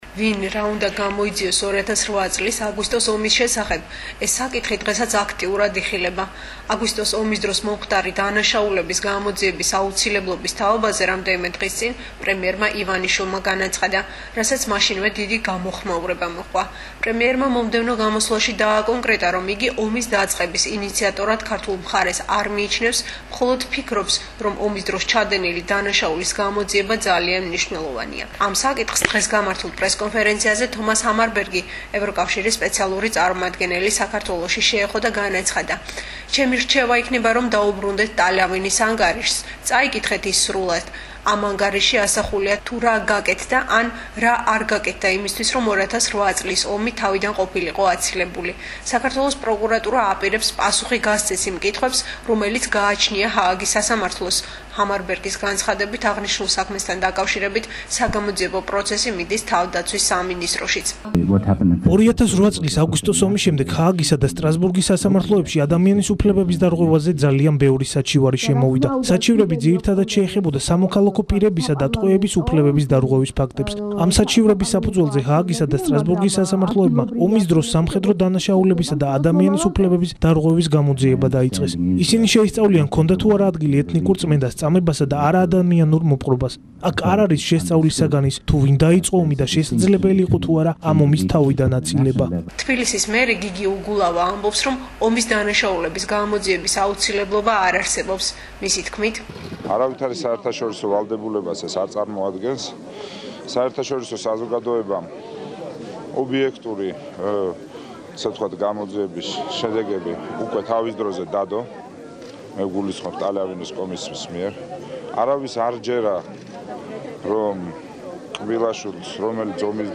ამ საკითხს დღეს გამართულ პრესკონფერენციაზე თომას ჰამარბერგი, ევროკავშირის სპეციალური წარმომადგენელი საქართველოში შეეხო